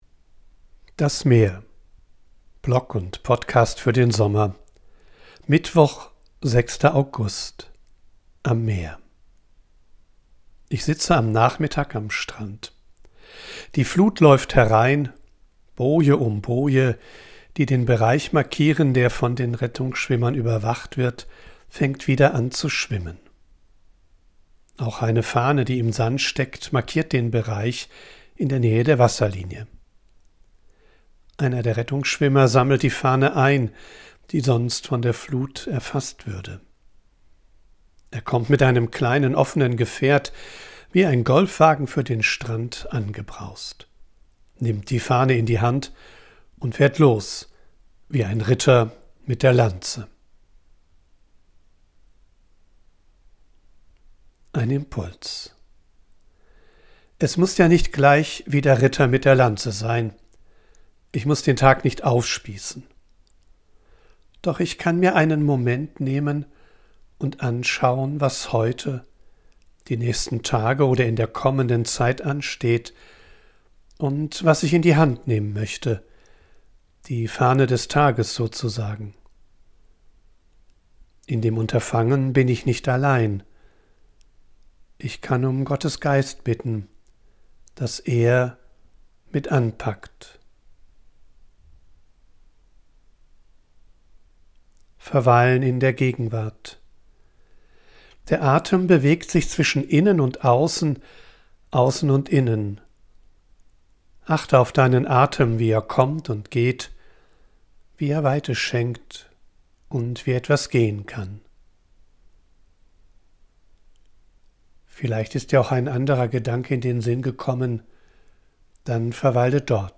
Ich bin am Meer und sammle Eindrücke und Ideen.